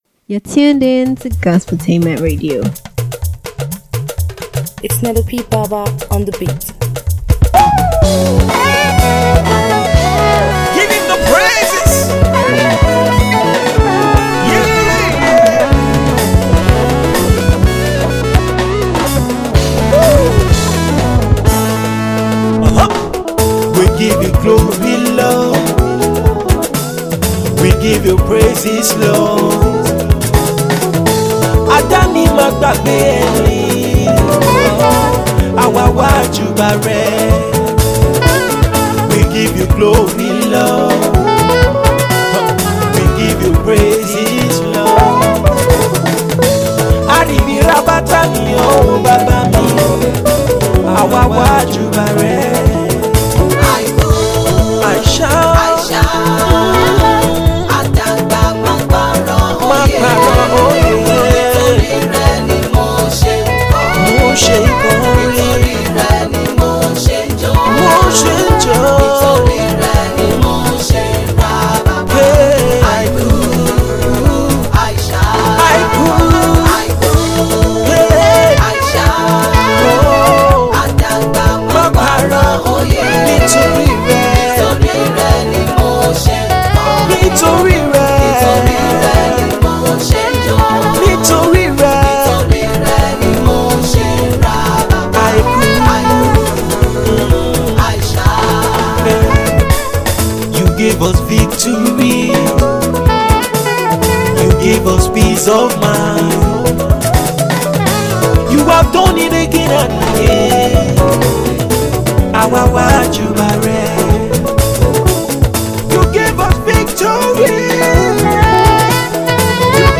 in Gospel Music, News
Gospel and inspirational Singer